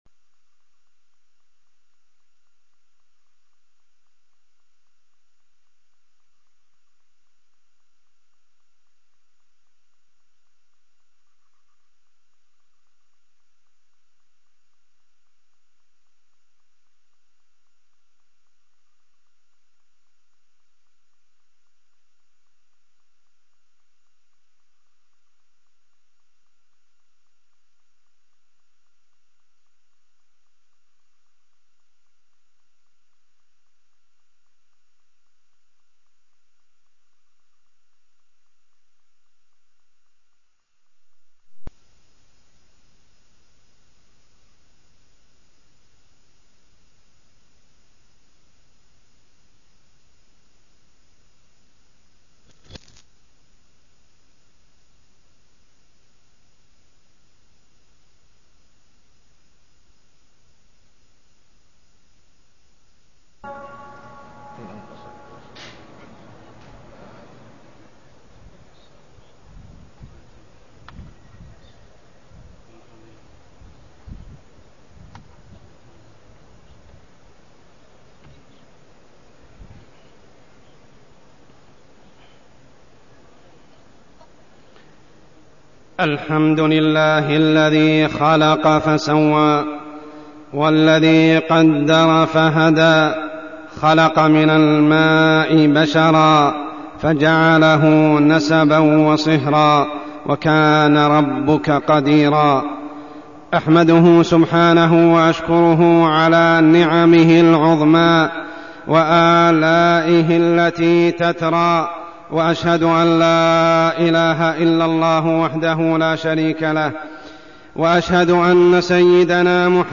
تاريخ النشر ١ ربيع الثاني ١٤١٩ هـ المكان: المسجد الحرام الشيخ: عمر السبيل عمر السبيل الحياة الزوجية